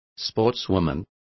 Complete with pronunciation of the translation of sportswomen.